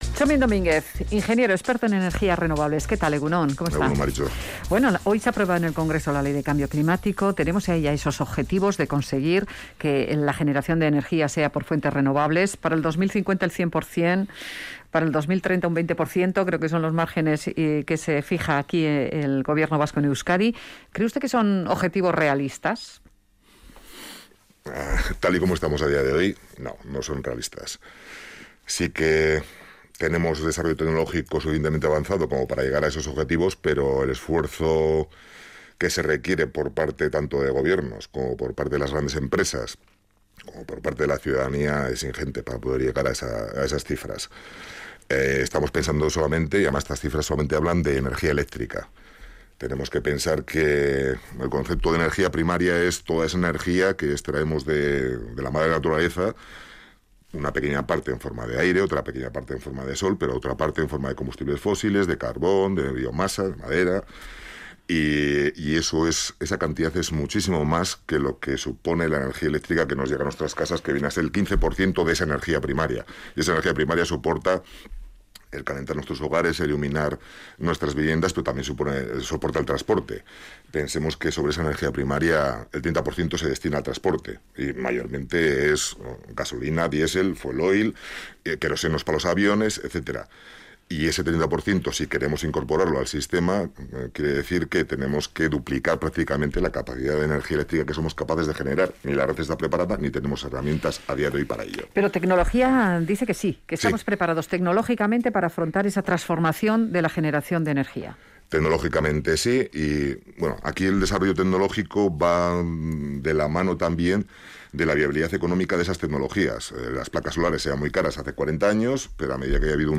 Entrevistado en Radio Vitoria